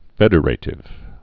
(fĕdə-rātĭv, fĕdər-ə-, fĕdrə-)